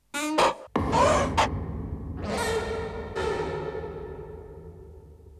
non compressé